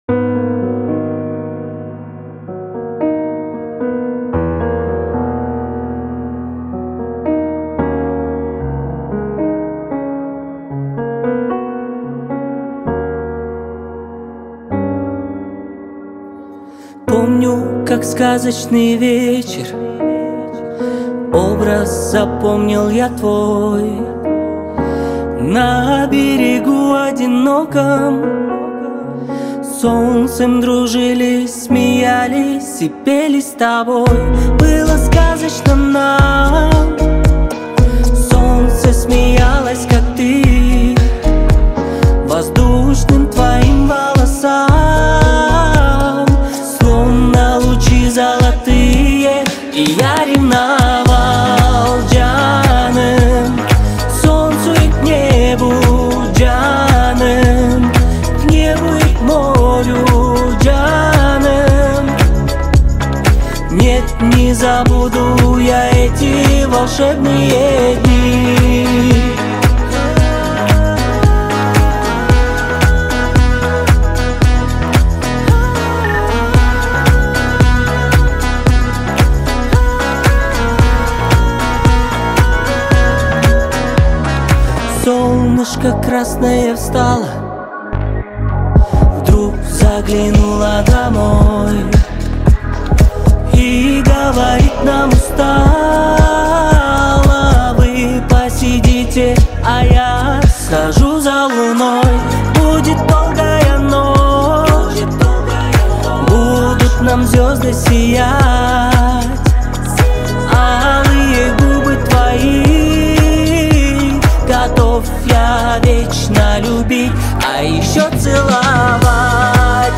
• Киргизские песни